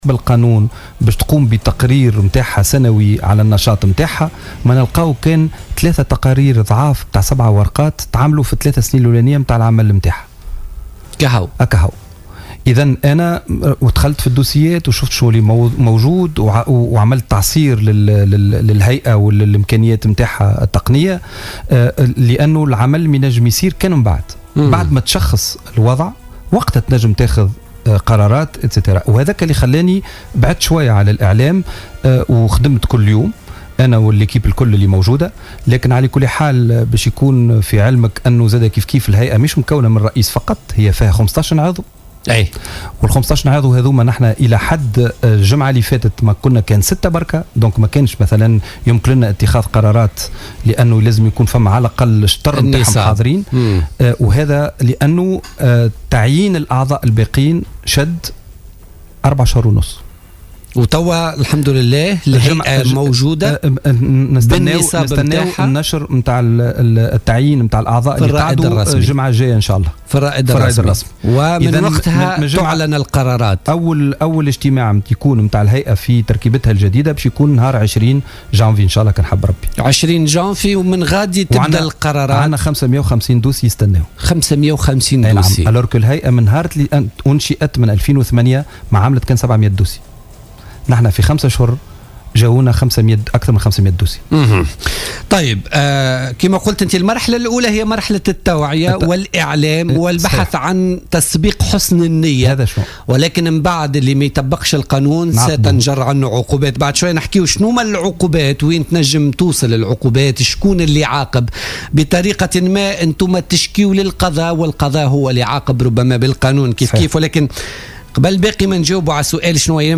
وأفاد المسؤول ذاته لـ "الجوهرة أف أم" في برنامج "بوليتيكا" أن المجلّة الجزائية تتضمن العقوبات المتعلقة بقضايا اختراق المعطيات الشخصية.